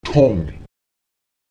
n [N] ähnelt dem ng in Ding.